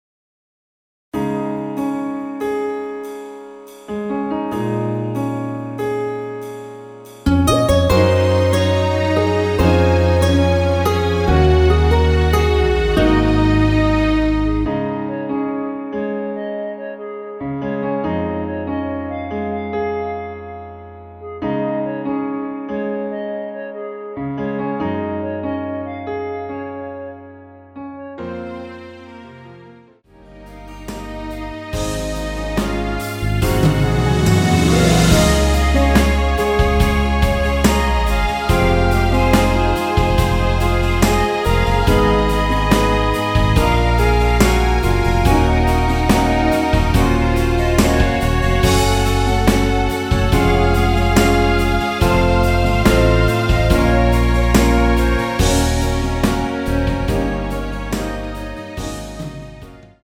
원키에서(-1)내린 멜로디 포함된 MR입니다.
Db
앞부분30초, 뒷부분30초씩 편집해서 올려 드리고 있습니다.
(멜로디 MR)은 가이드 멜로디가 포함된 MR 입니다.